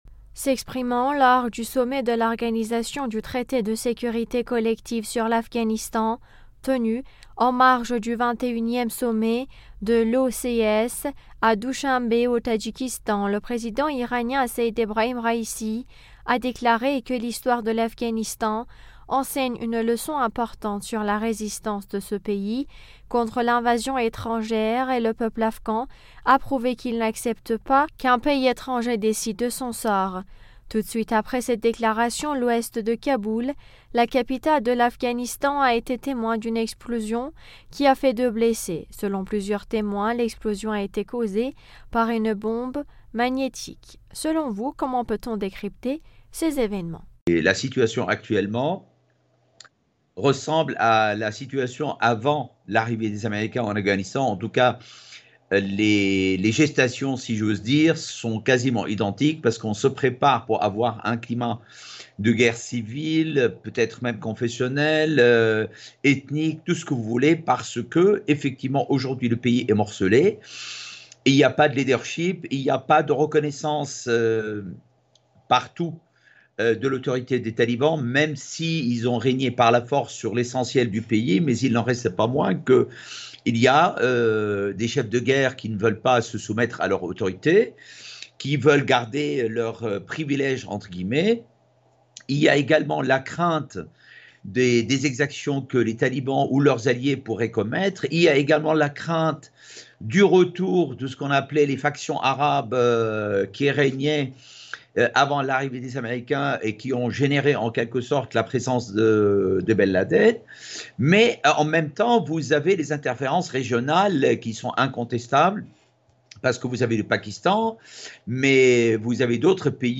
Mots clés Afghanistan Raïssi interview Eléments connexes Pourquoi Trump convoite la base militaire de Bagram en Afghanistan ?